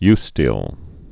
(ystēl, y-stēlē)